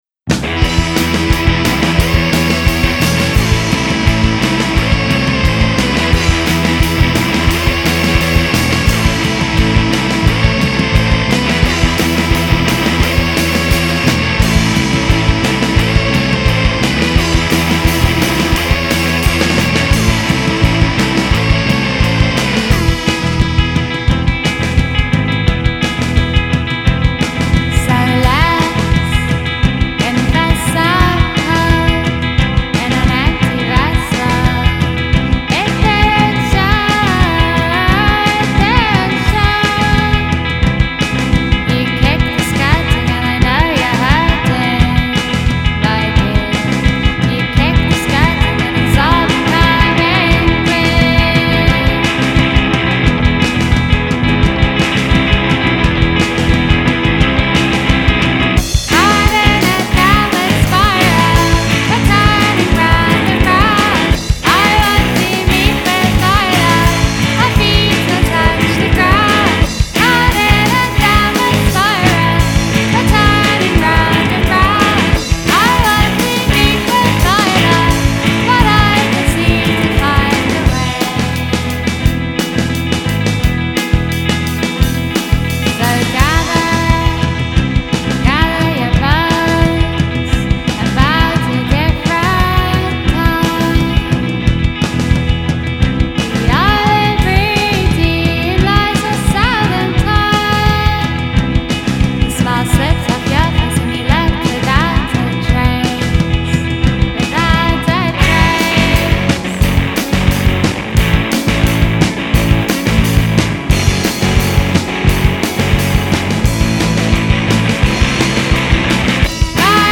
with more of their dreamy pop in tow
is easily the liveliest of all the tracks in this lovely 7″